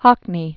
(hŏknē), David Born 1937.